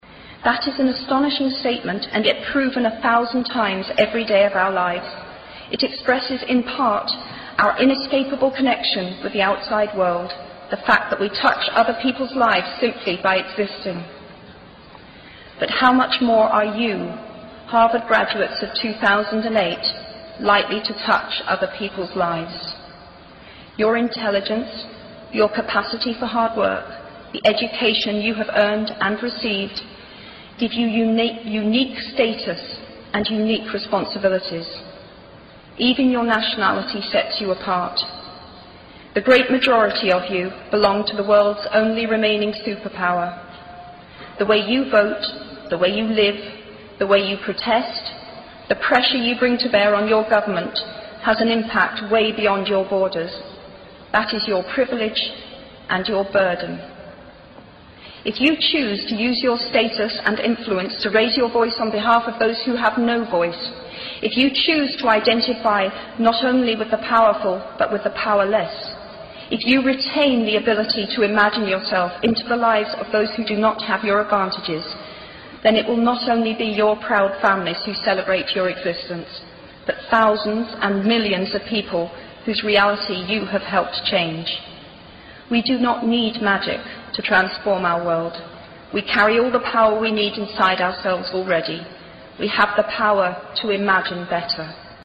像励志英语演讲 28:失败的好处和想象的重要性(10) 听力文件下载—在线英语听力室
在线英语听力室像励志英语演讲 28:失败的好处和想象的重要性(10)的听力文件下载,《偶像励志演讲》收录了娱乐圈明星们的励志演讲。